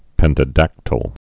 (pĕntə-dăktəl) also pen·ta·dac·ty·lous (-tə-ləs)